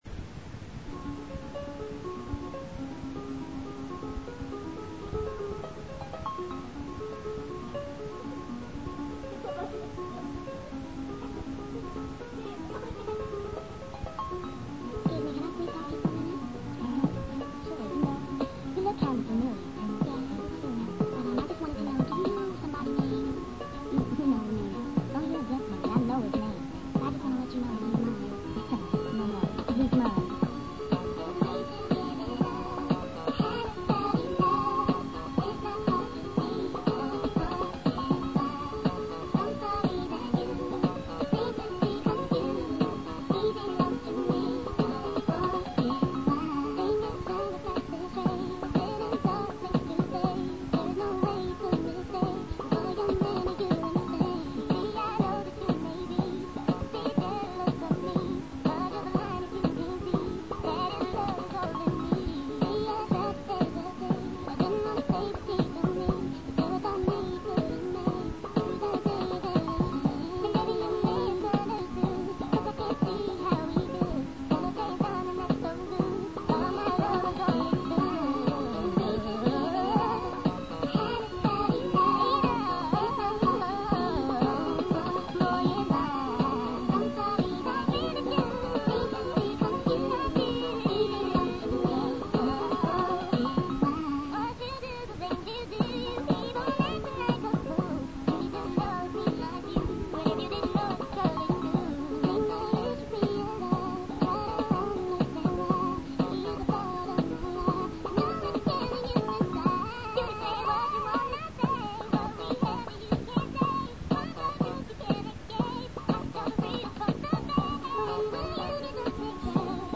Know of a song you want to hear chipmunk-ized?